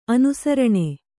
♪ anusaraṇe